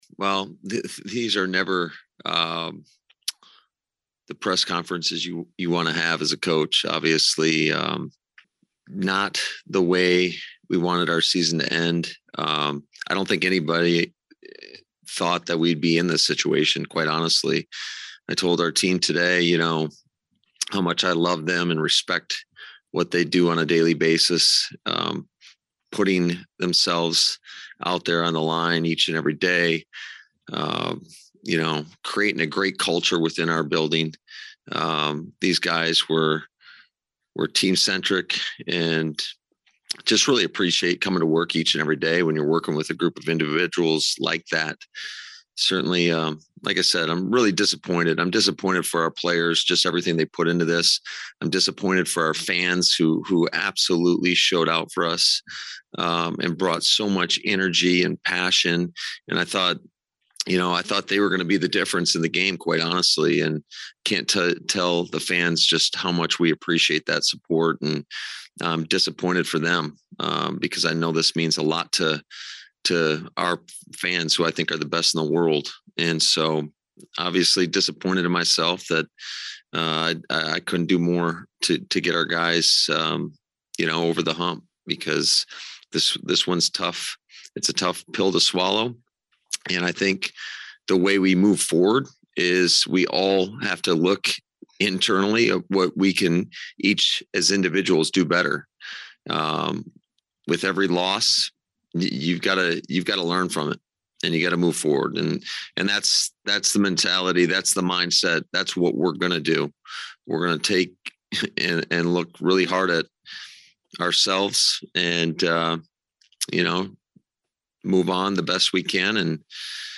A somber Matt LaFleur meets the press one last time.
Before fielding questions, LaFleur began with a soul-searching opening to his 2021 farewell address.